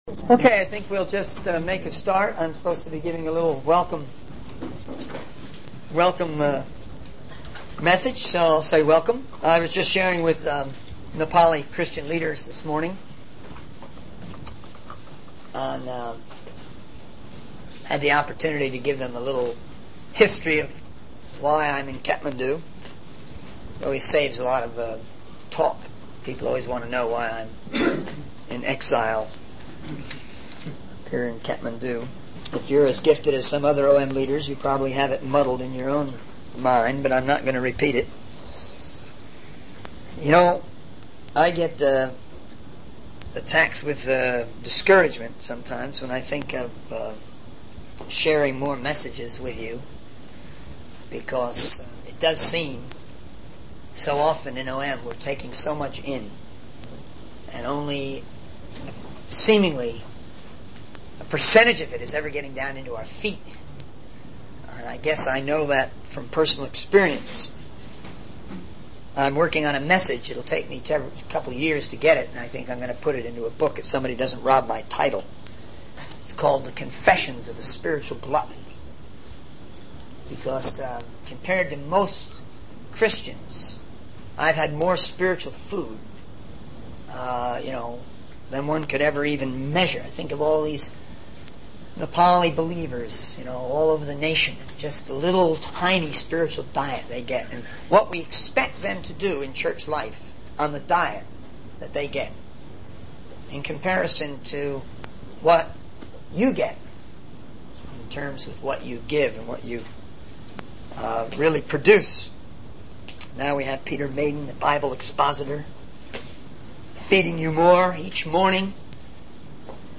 In this sermon, the speaker reflects on the past year and how it challenged the basic principles of Operation Mobilization. He emphasizes the importance of holding fast to the principles given by God while also being open to change. The speaker then delves into the teachings of James 1, highlighting the need to be swift to hear, slow to speak, and slow to anger.